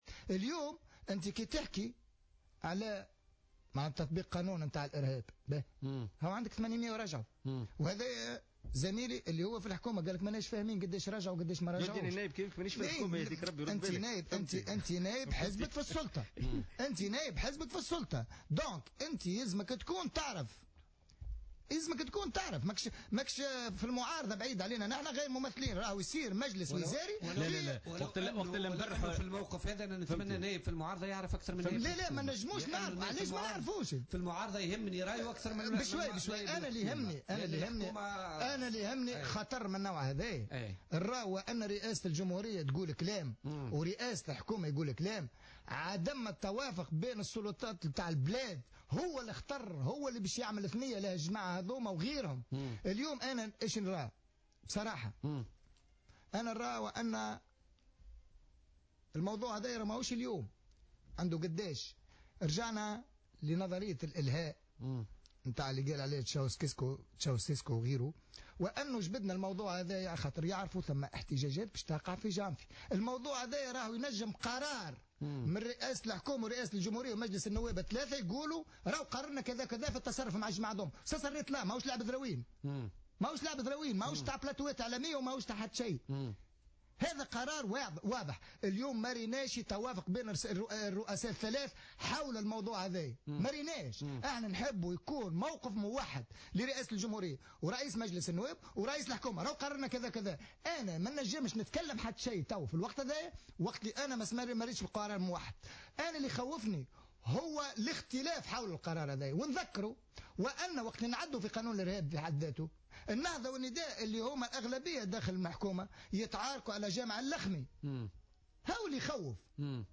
طالب رئيس حزب الفلاحين فيصل التبيني خلال استضافته اليوم الأربعاء في برنامج "بوليتكا"، وزير الداخلية بنشر قائمة الإرهابيين التونسيين في بؤر التوتر.